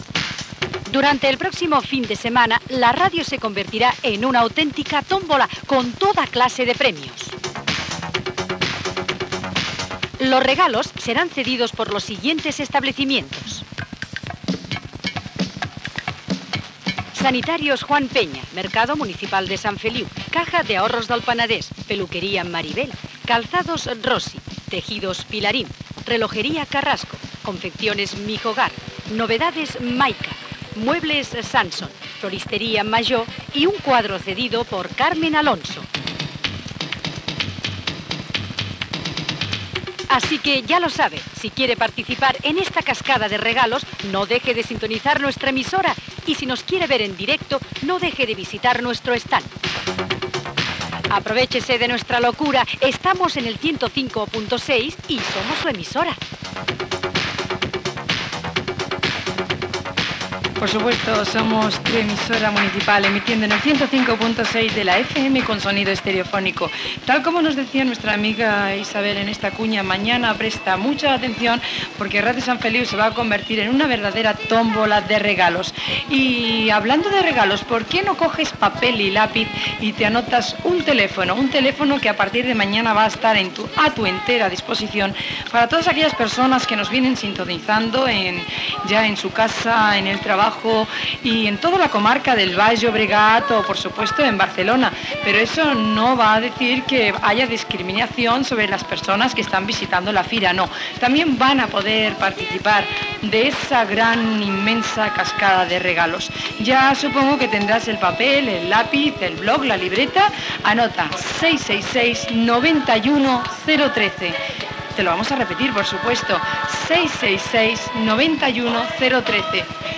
Anunci de la tómbola de la ràdio a la Fira Comercial i Industrial del Baix Llobregat.